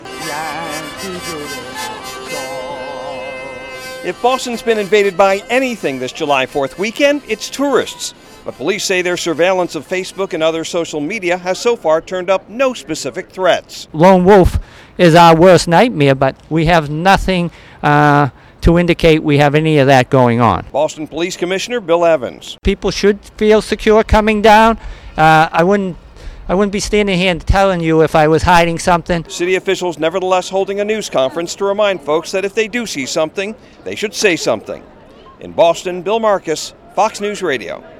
Boston Mayor Marty Walsh at a morning news conference outside city hall to remind citizens to practice safe grilling on July 4th. Police officials say they have no indication of terrorist threats.